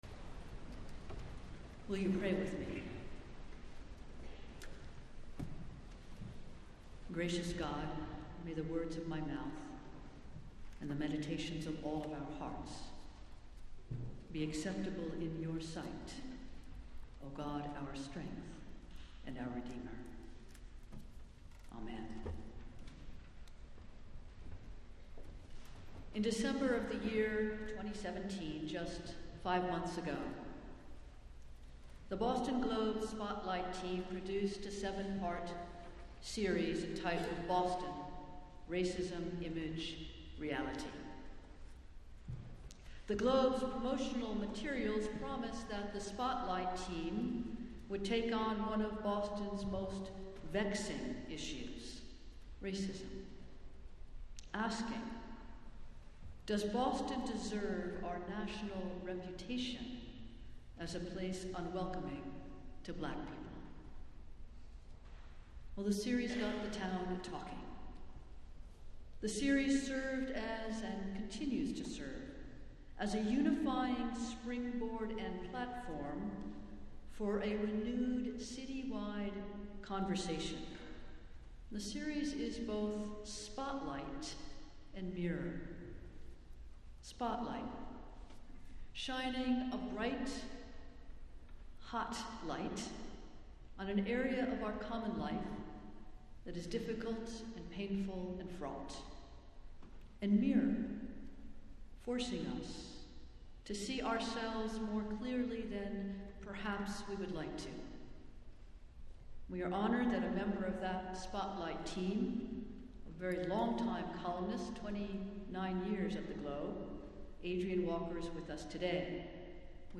Festival Worship - Phillis Wheatley Sunday